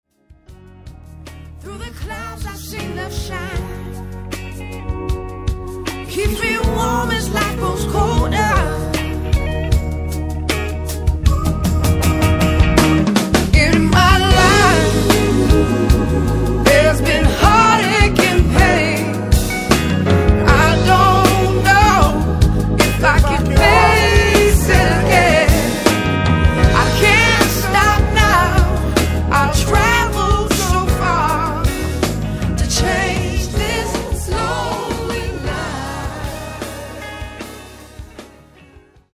往年のSOUL/FUNKクラシックを完璧に歌いこなす珠玉のカバー集を緊急リリース!